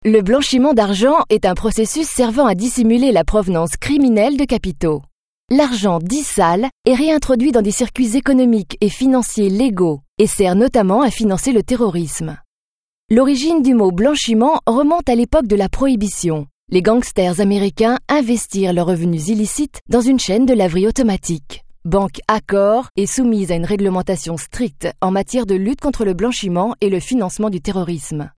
Sprechprobe: eLearning (Muttersprache):
Smooth, professional, trustworthy or sweet, her voice plays in versatile ranges: Colorful, honest, silky, warm, alluring & deep, Parisian..